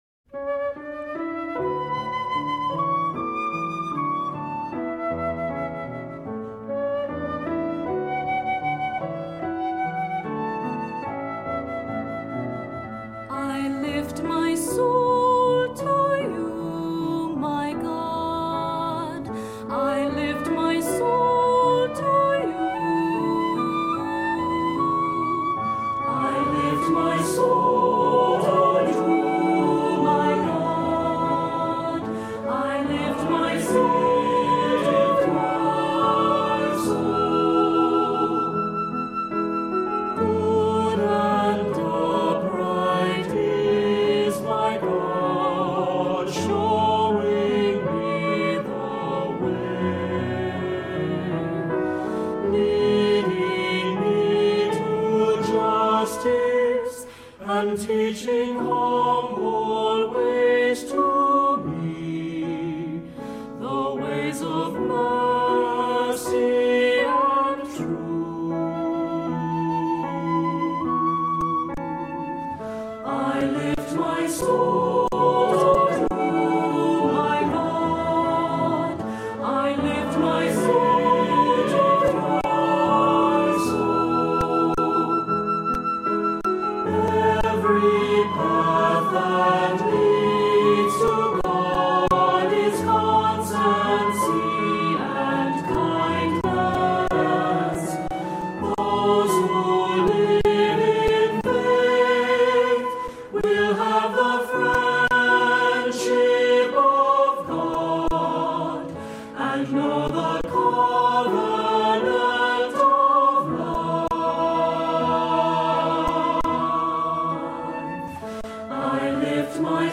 Voicing: "SATB, assembly, cantor"